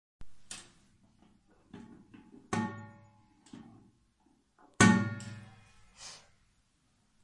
撞碎玻璃瓶
描述：把一些玻璃瓶子扔到车库里。一些小生态。用"Roland Air Recorder"在Iphone 4S上录制。
标签： 崩溃 垃圾 垃圾桶 玻璃